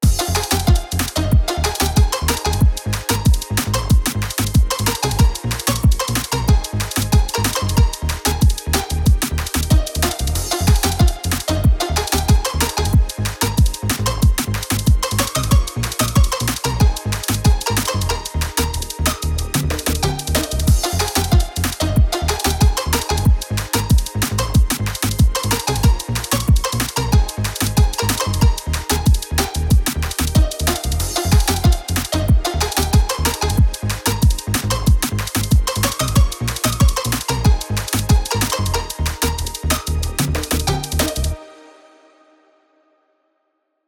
Splat (Glitchy Lofi DnB Track